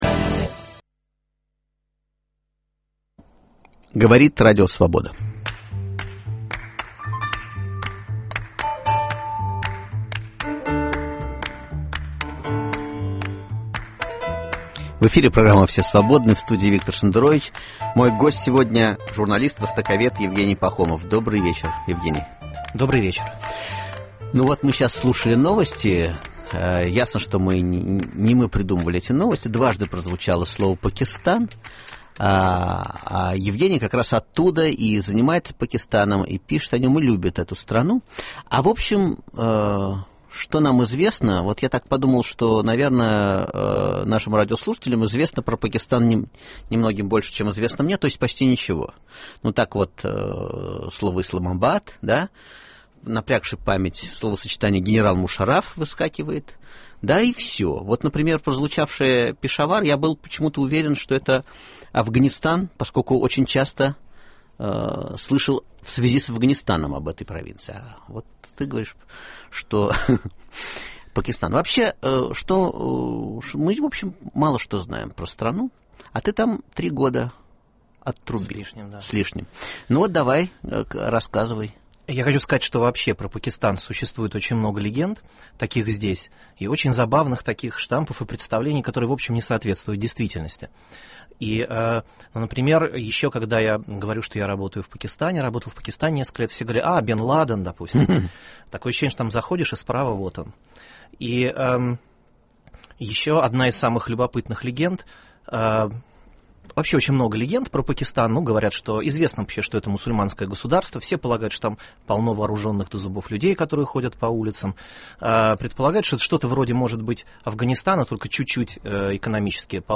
Воскресный разговор на свободные темы.